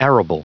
Prononciation du mot arable en anglais (fichier audio)
Prononciation du mot : arable